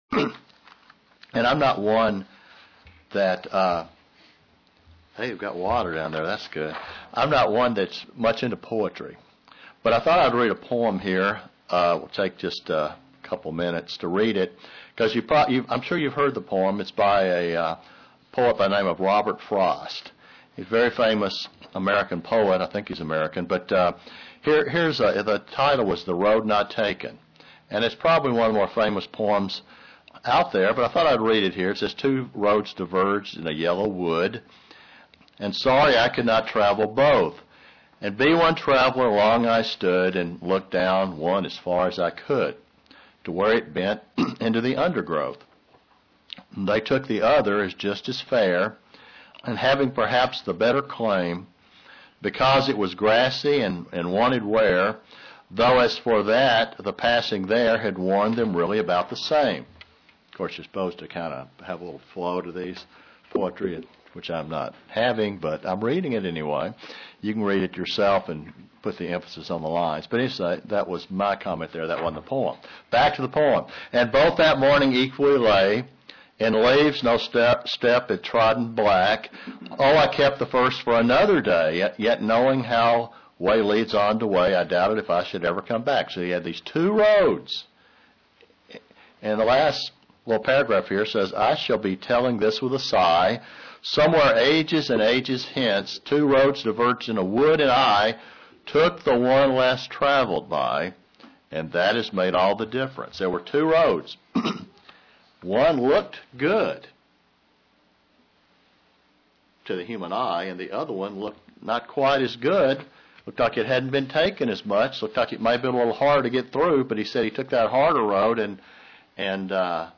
Given in London, KY
Print Two possible roads in life and the one less traveled is the better one UCG Sermon Studying the bible?